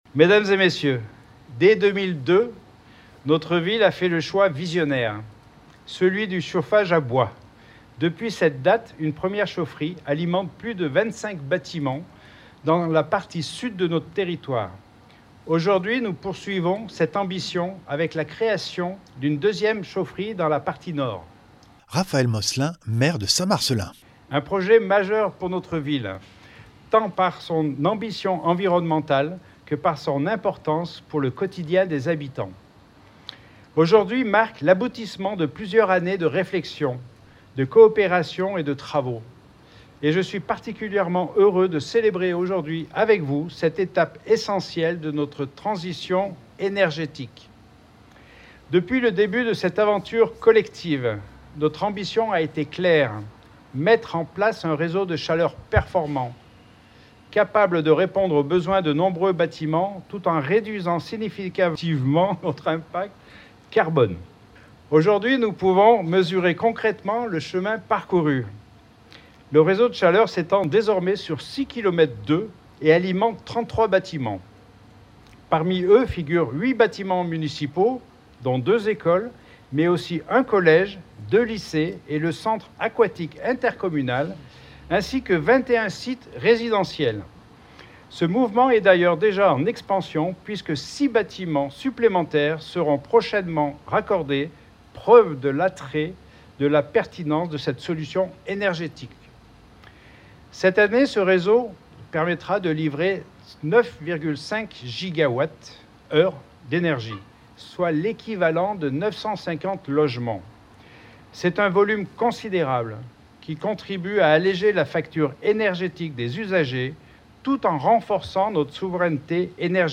chaufferie-bois-St-Marcellin-inauguration-2-12-25.mp3